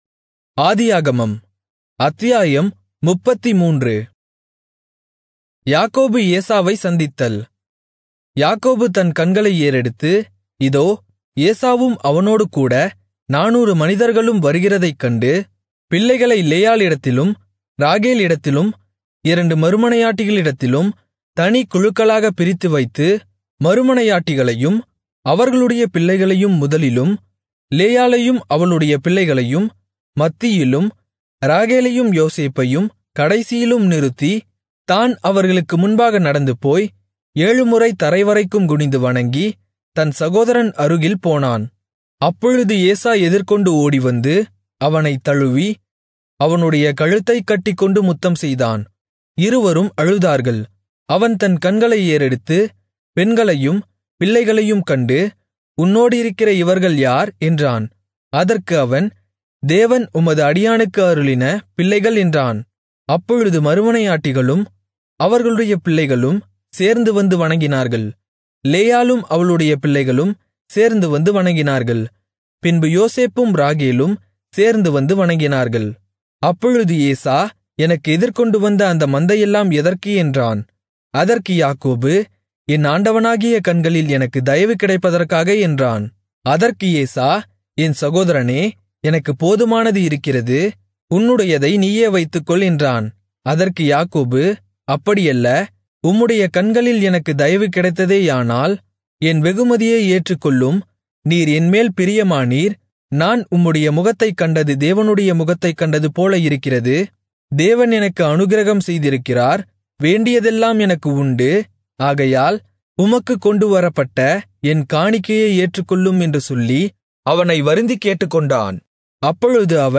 Tamil Audio Bible - Genesis 17 in Irvta bible version